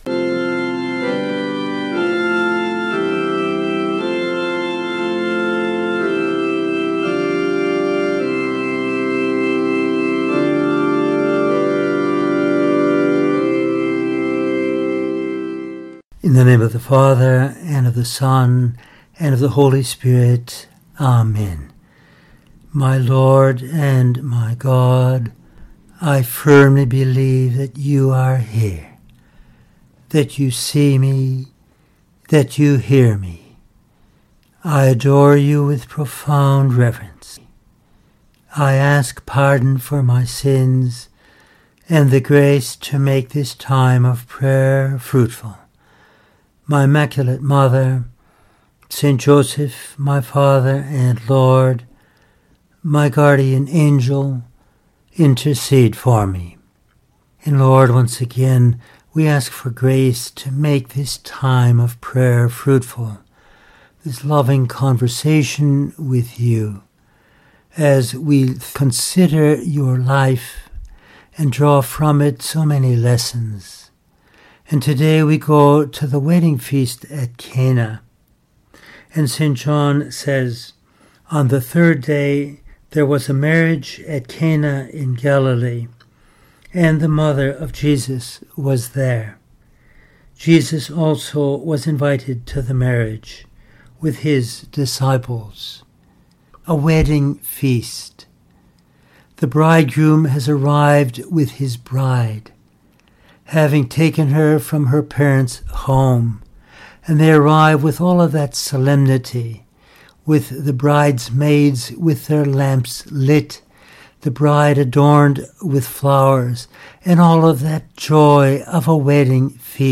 Our Lord’s first miracle at the wedding feast of Cana has much to teach us. In this meditation we use texts of St Josemaria, Tertullian and St John Vianney to consider some of these lessons: